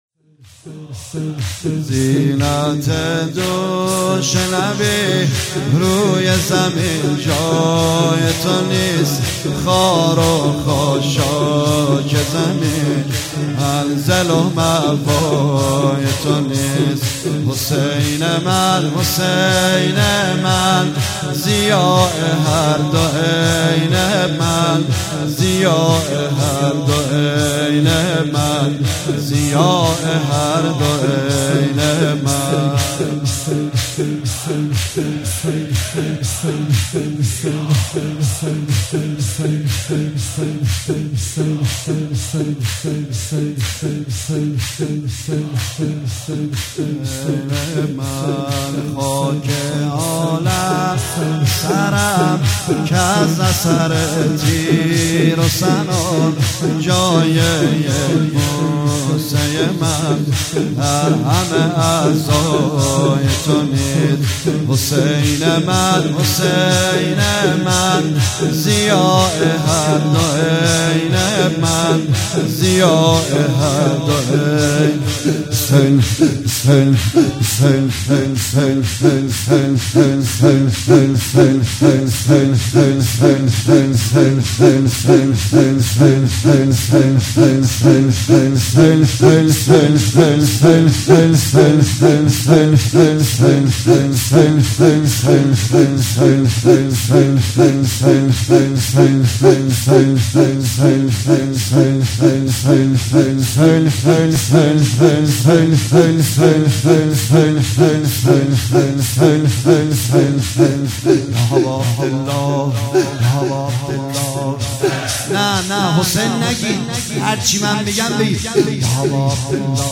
مراسم شب اول محرم 1438 هیئت رزمندگان مکتب الحسین(ع)